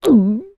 Звуки глотка